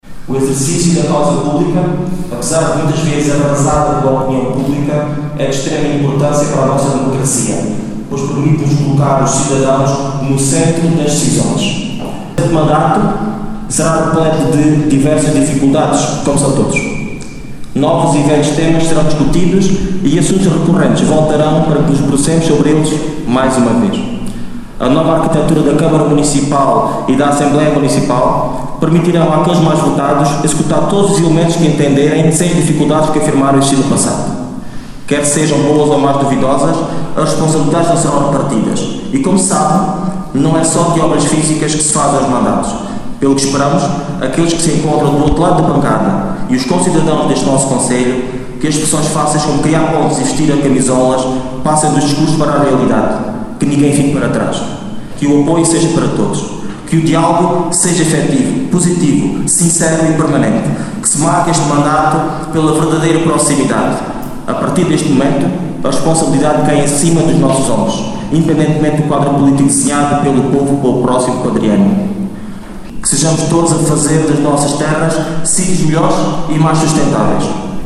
Teve lugar ontem, o Ato de Instalação dos Órgãos do Município de Vidigueira, Assembleia e Câmara Municipal, com a tomada de posse dos novos eleitos para o quadriénio 2021/2025.